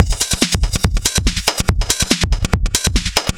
Index of /musicradar/uk-garage-samples/142bpm Lines n Loops/Beats